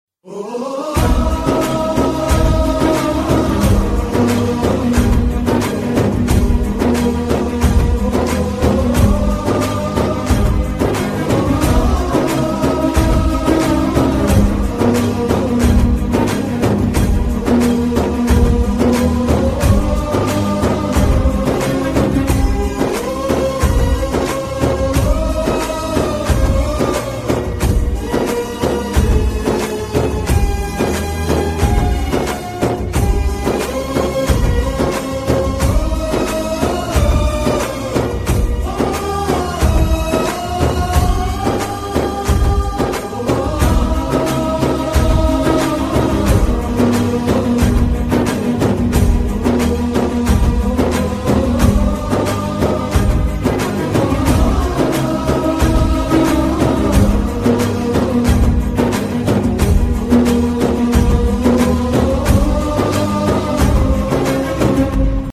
Humming